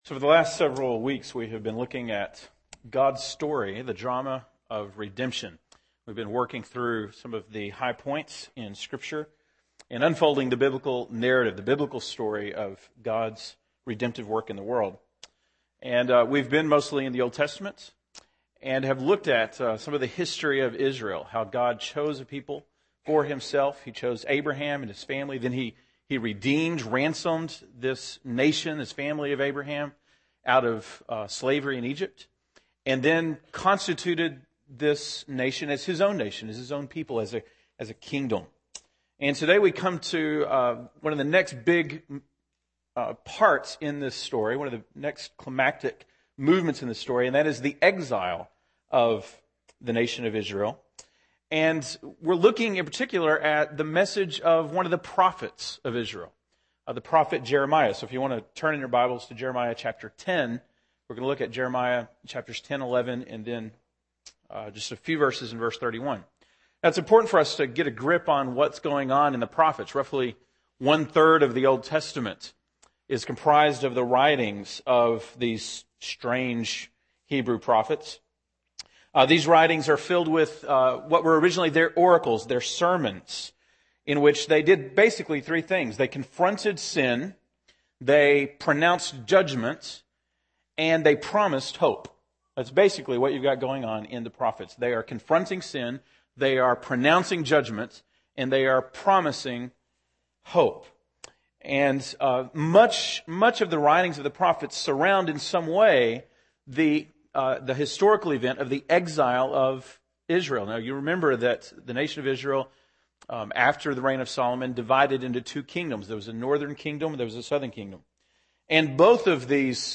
March 21, 2010 (Sunday Morning)